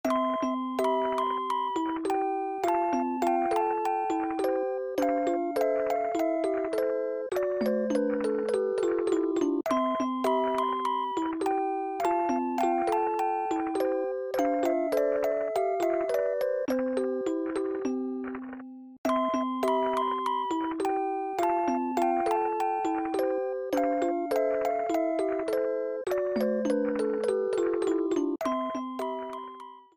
Music box theme